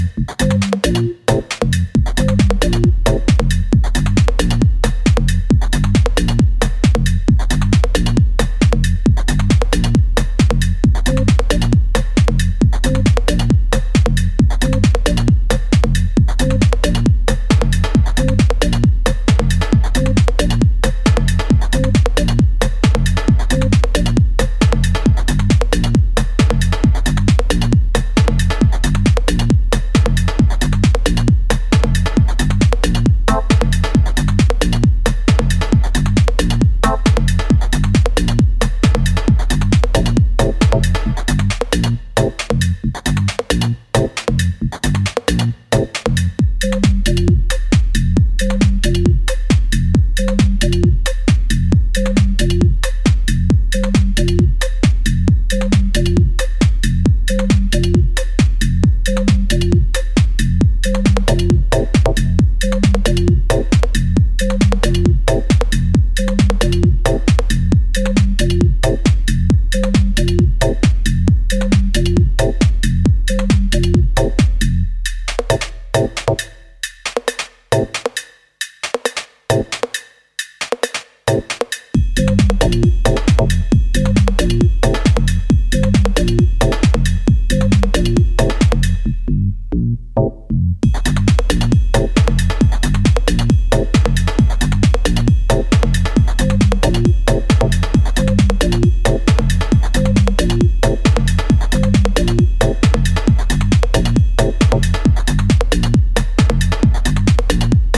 minimal house